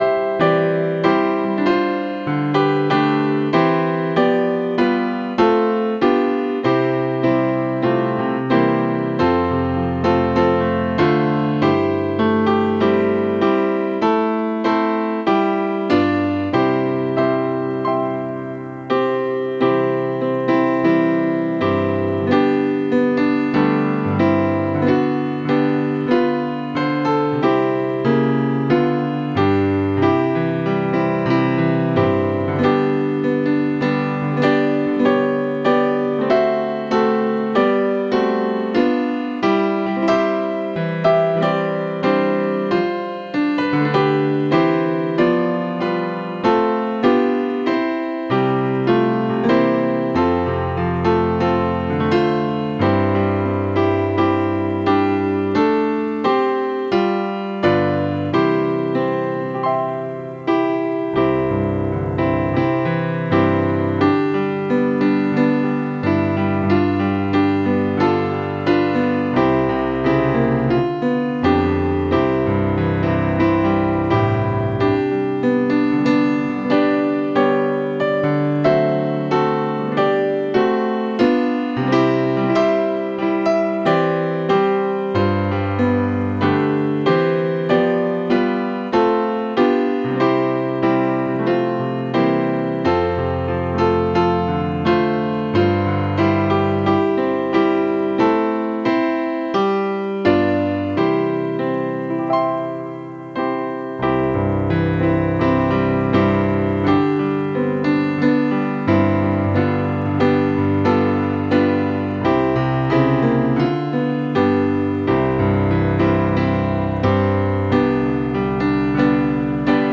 12-6-20piano.wav